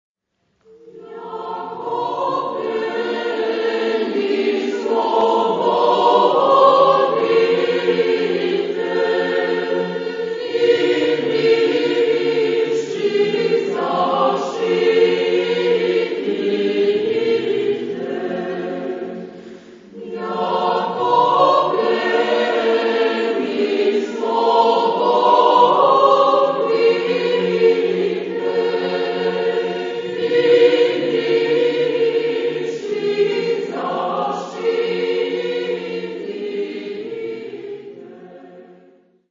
Lokalisierung : 20ème Sacré Acappella
SATB (4 gemischter Chor Stimmen )
Tonart(en): F-Dur